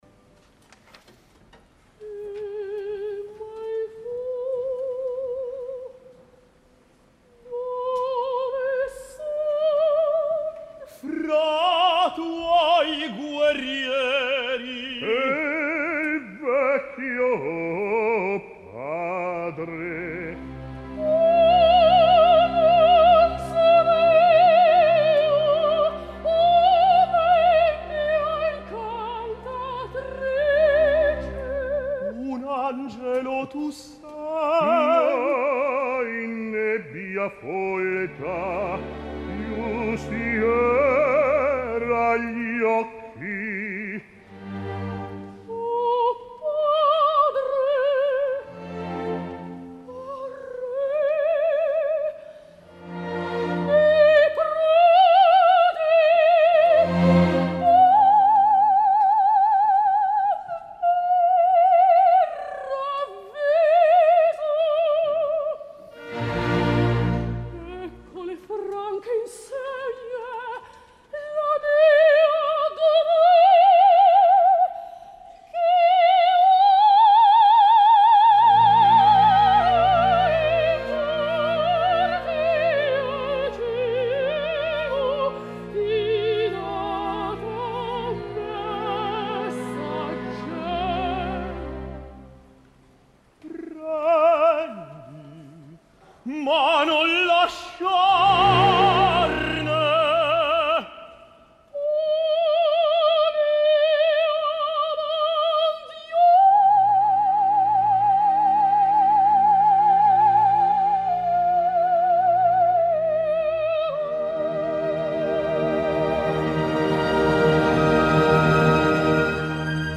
Dramma lirico in quattro atti
Carlo VII:  Francesco Meli
Giovanna:  Anna Netrebko
Cor i Orquestra del Teatro alla Scala
Director musical: Riccardo Chailly
Teatro alla Scala, Milano 7 de desembre de 2015